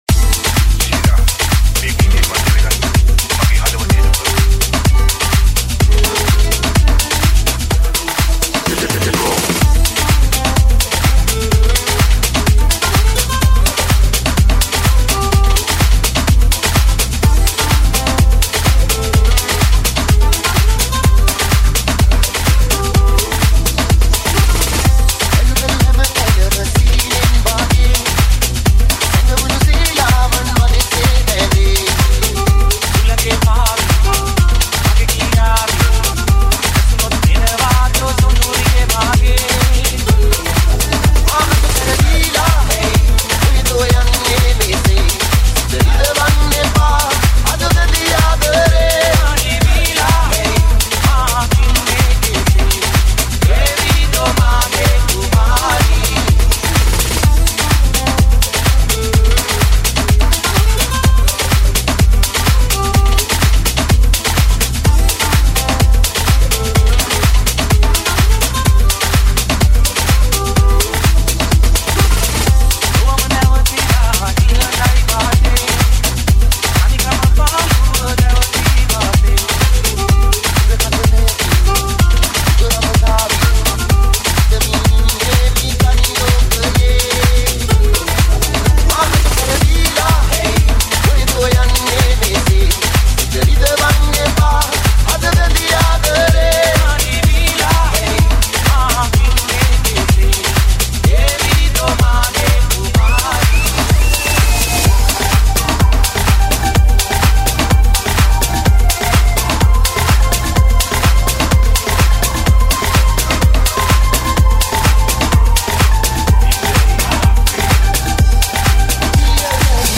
High quality Sri Lankan remix MP3 (16).